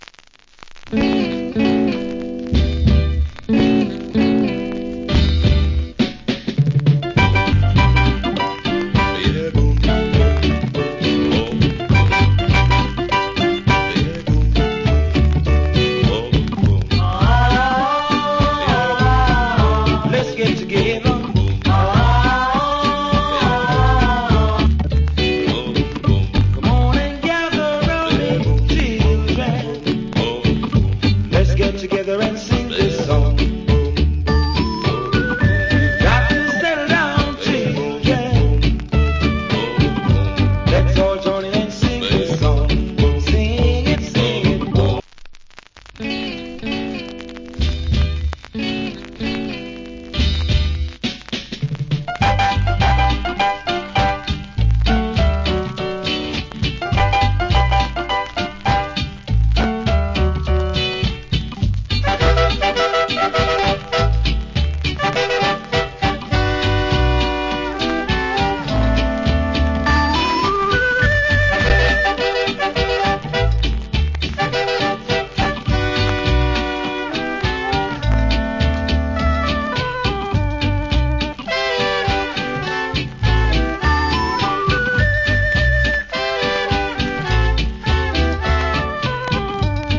category Ska
Great Early Reggae Vocal. / Inst Cut.